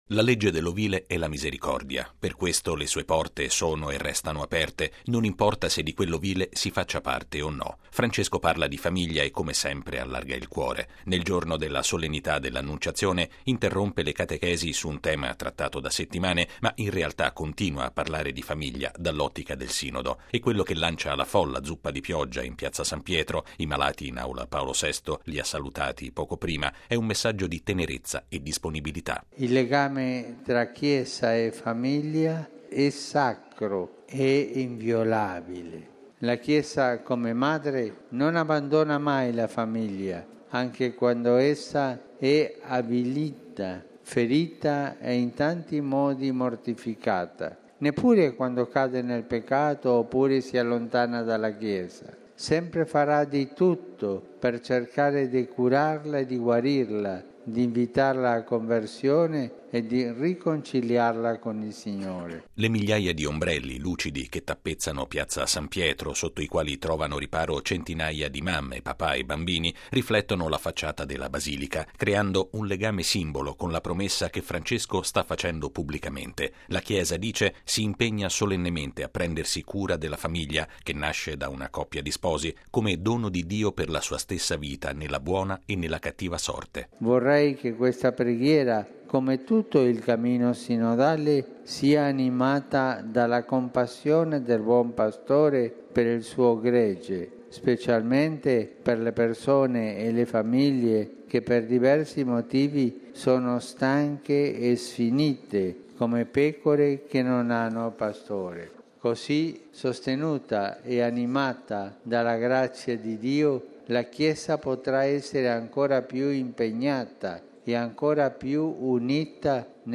Bollettino Radiogiornale del 25/03/2015